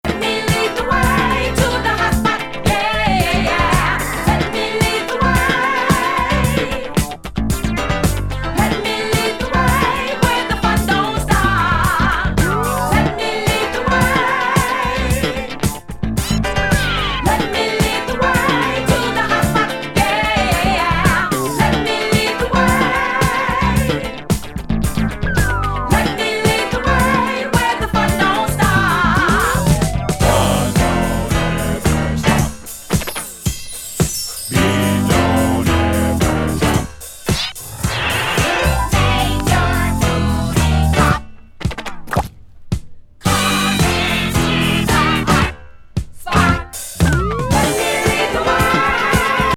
受け満点な好グルーヴ・チューン揃い。